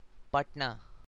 Patna (/ˈpʌtnə, ˈpæt-/;[14][15] Hindi: [ˈpəʈnaː]
Patna.ogg.mp3